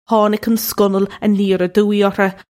Pronunciation for how to say
haw-nig un skun-null an-yeer a-duu-ee urr-hah
This is an approximate phonetic pronunciation of the phrase.